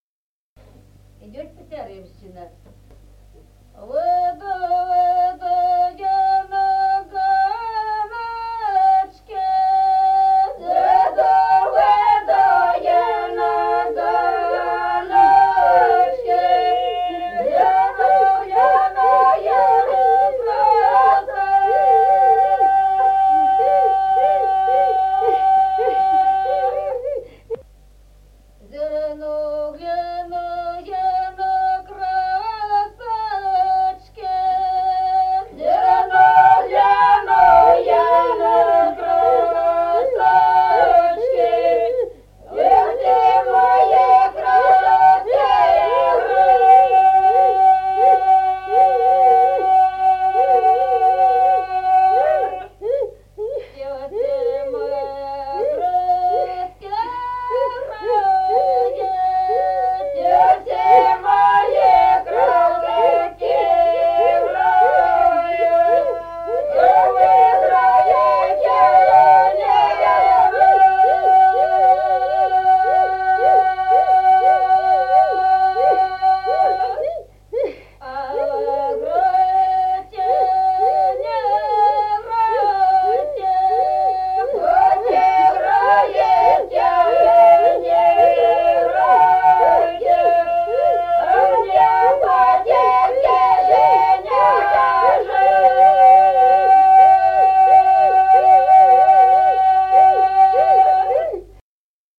Песни села Остроглядово. Выйду, выйду я на ганочки (с плачем).